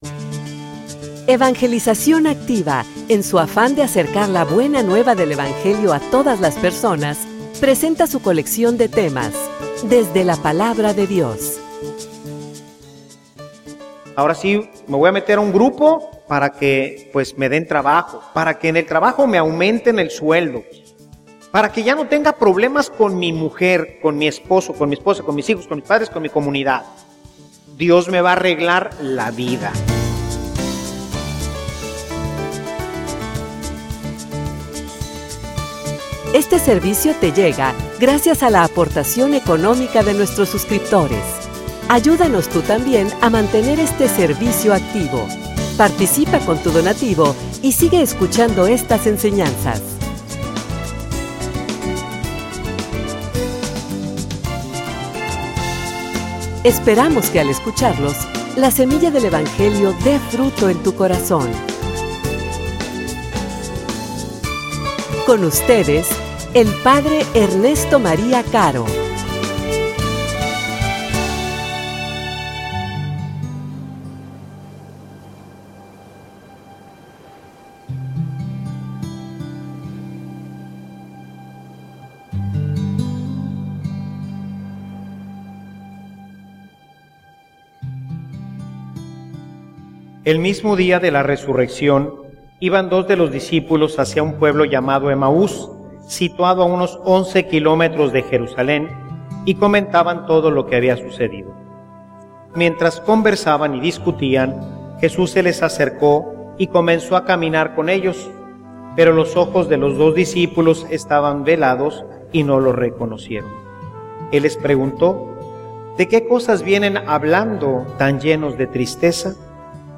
homilia_Encuentro_con_los_decepcionados.mp3